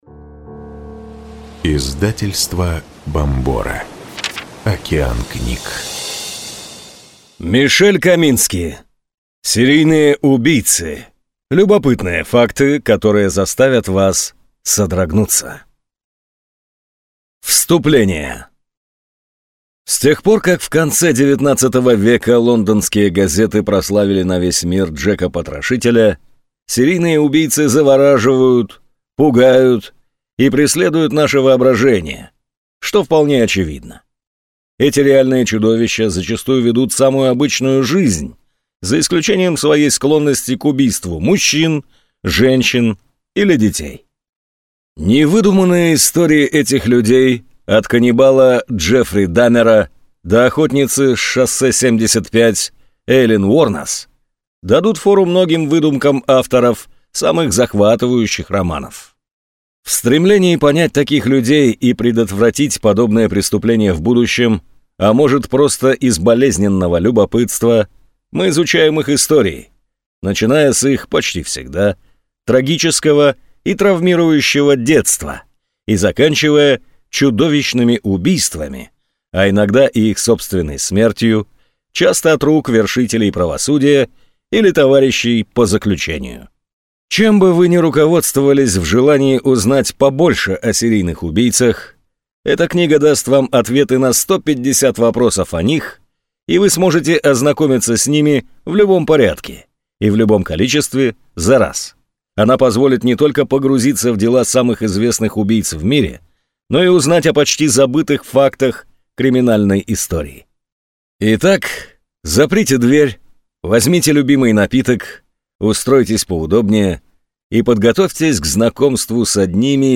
Аудиокнига Серийные убийцы. Любопытные факты, которые заставят вас содрогнуться | Библиотека аудиокниг